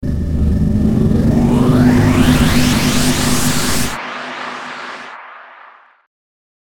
/ F｜演出・アニメ・心理 / F-30 ｜Magic 魔法・特殊効果
ボワーァン